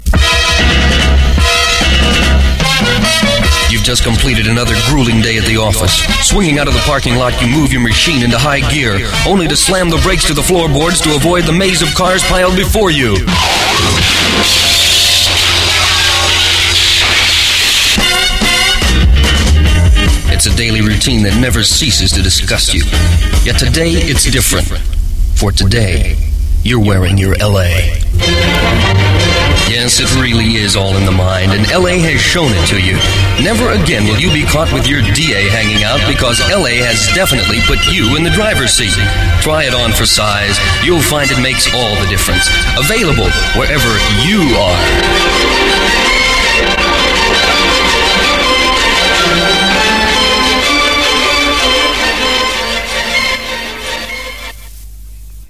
Loving Awareness promotions